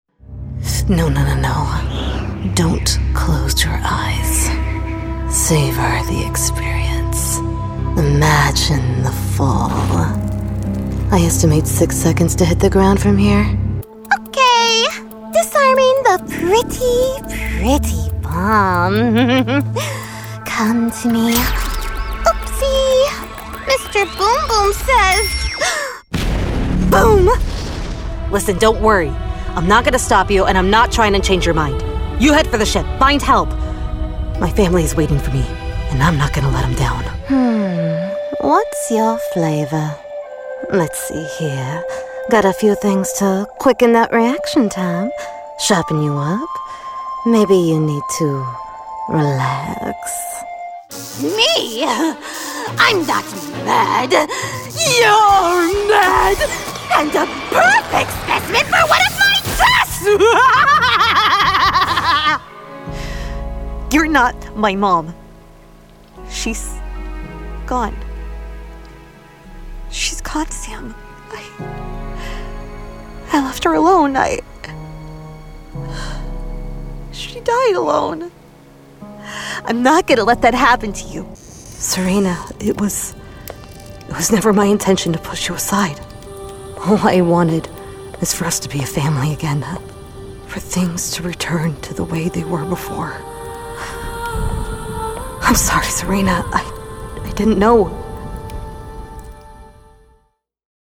Anglais (américain)
Échantillons de voix natifs
Jeux vidéo